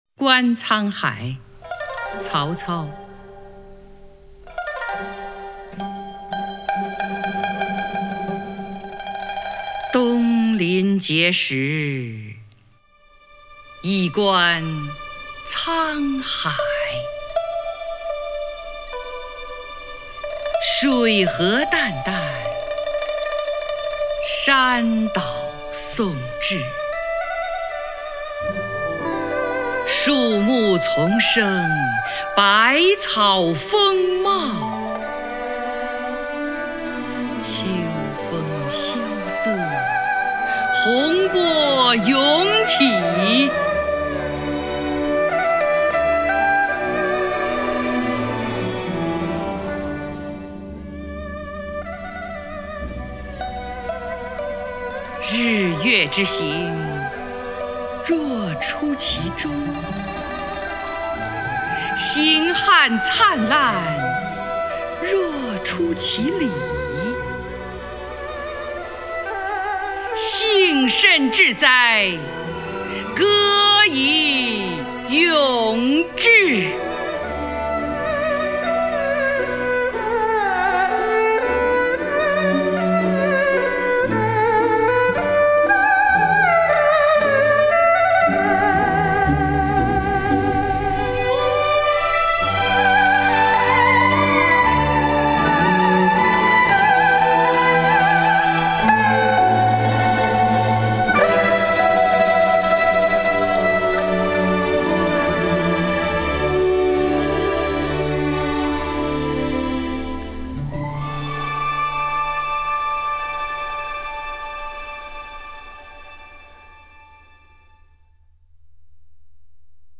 张筠英朗诵：《观沧海》(（东汉）曹操)
名家朗诵欣赏 张筠英 目录